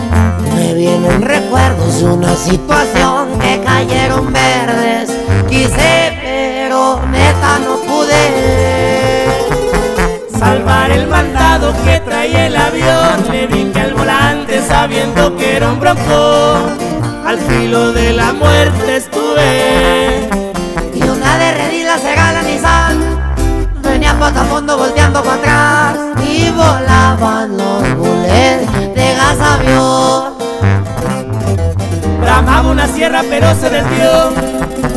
Música Mexicana, Latin